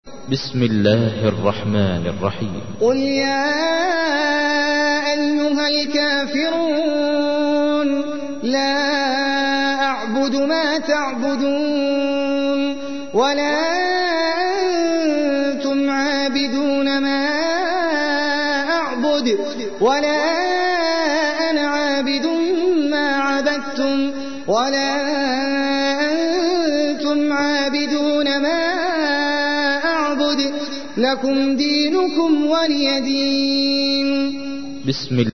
تحميل : 109. سورة الكافرون / القارئ احمد العجمي / القرآن الكريم / موقع يا حسين